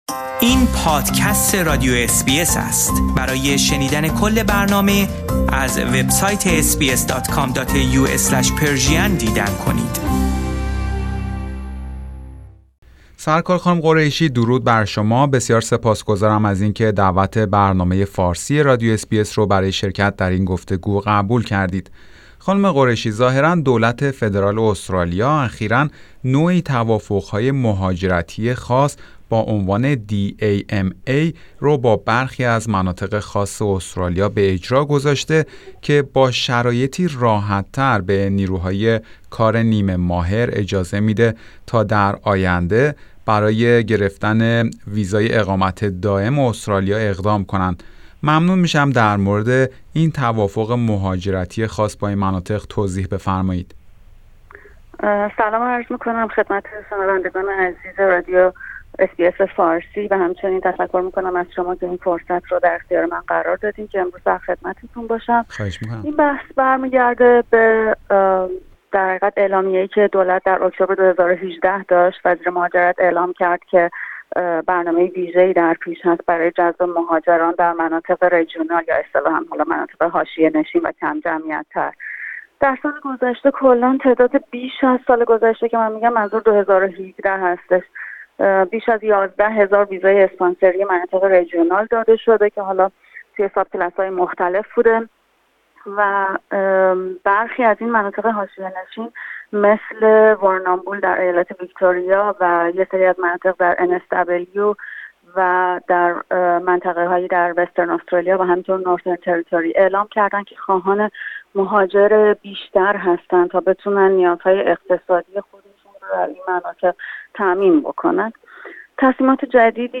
در همین خصوص گفتگویی داشتیم